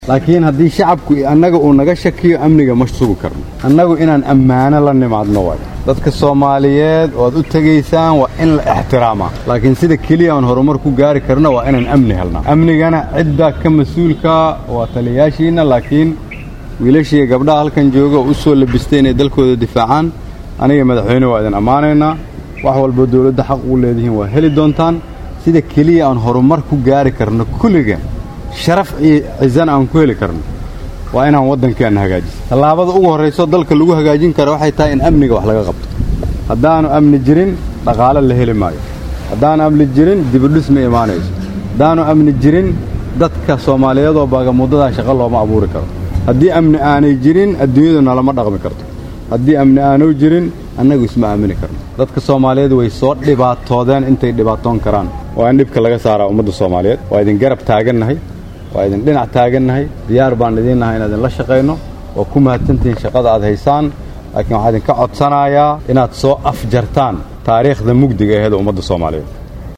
Cod-Raiisalwasaha-1.mp3